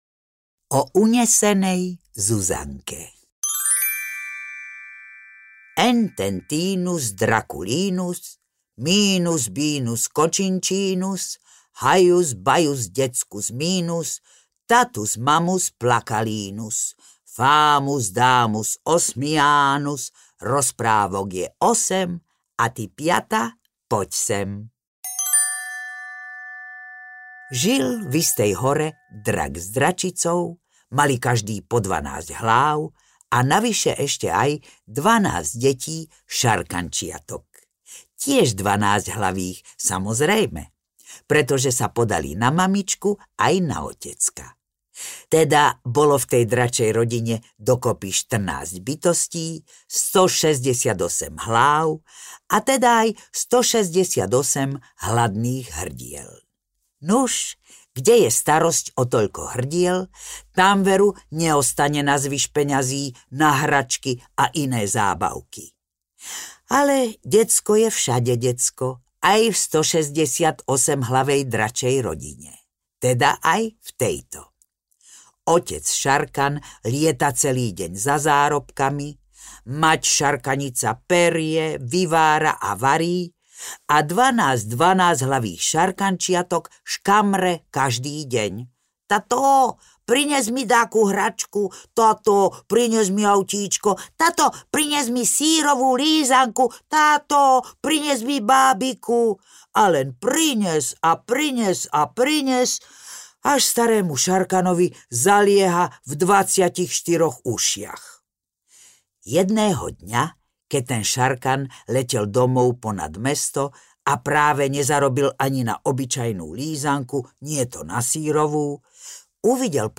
Ukázka z knihy
Okrem Osmidunča sa zoznámite aj s veselým prasiatkom Osmičunčom a čaká vás veľa zábavy nielen pri prasiatkovských, ale aj pri nočných, dračích, školských, hudobných, prekážkových, štefanských či porekadlových rozprávkach!Sprevádzať vás bude opäť osmička skvelých hercov.